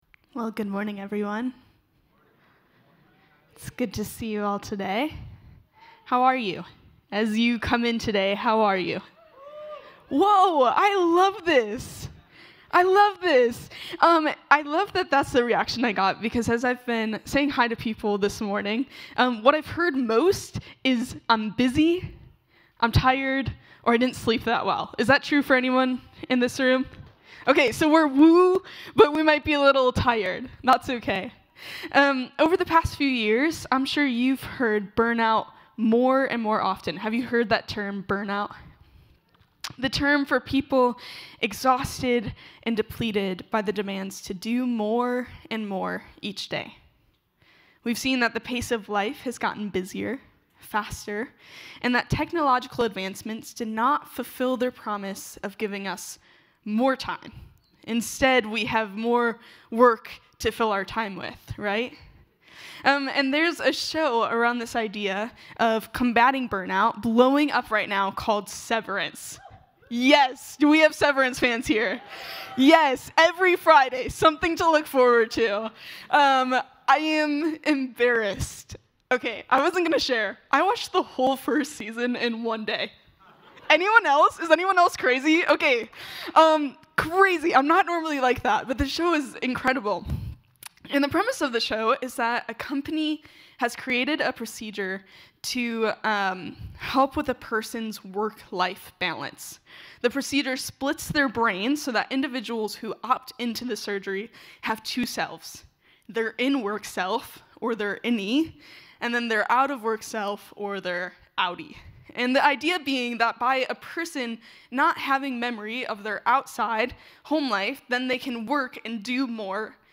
brings this morning's message.